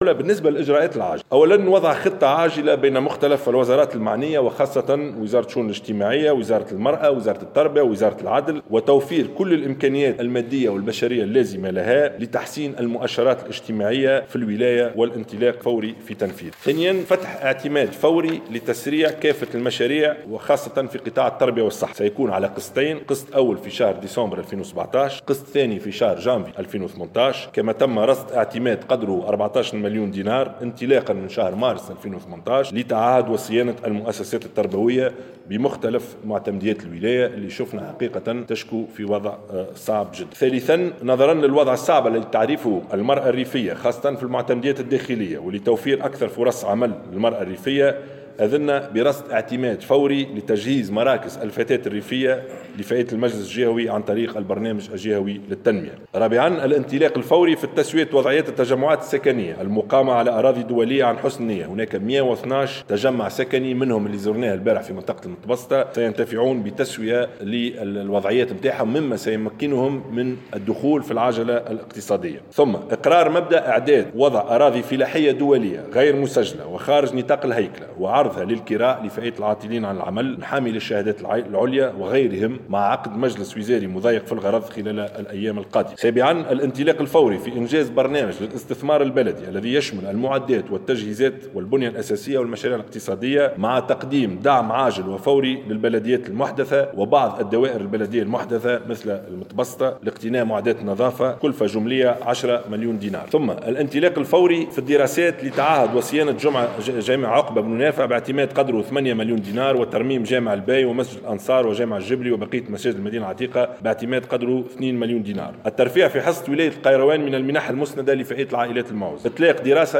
أعلن رئيس الحكومة يوسف الشاهد اليوم الخميس اثر المجلس الوزاري الخاص بولاية القيروان، عن جملة من القرارات العاجلة لفائدة الجهة.